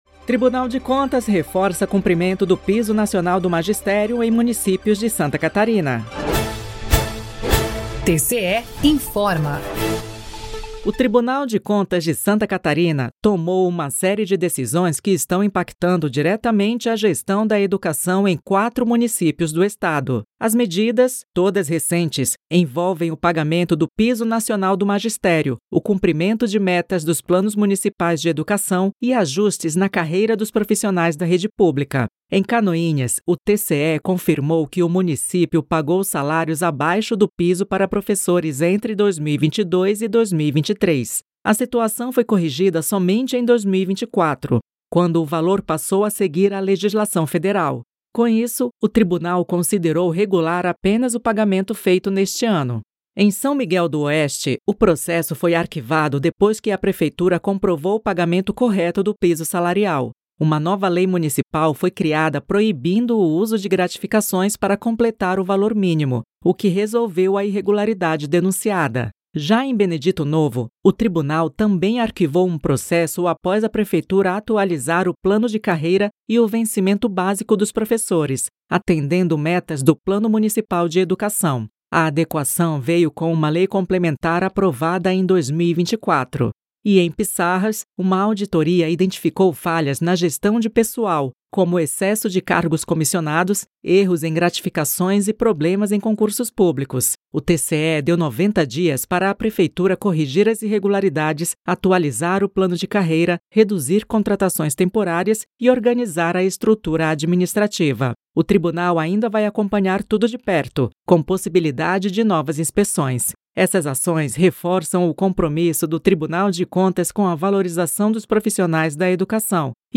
VINHETA TCE INFORMOU